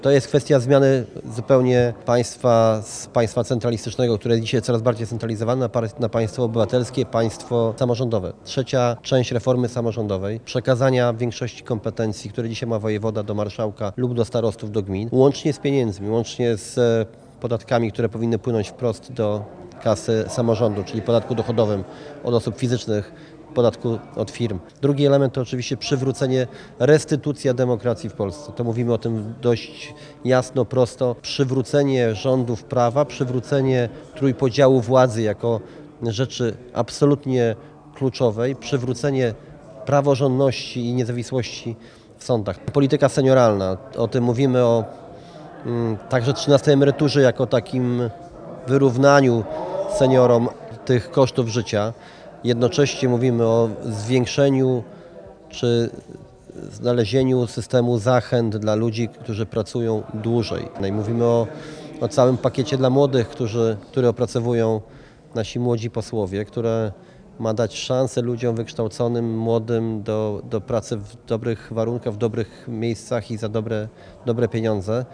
O jego głównych punktach, mówił Radiu 5 Sławomir Neumann, przewodniczący PO.